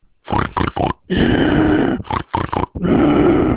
growling oink sound.
PiggyMossum.wav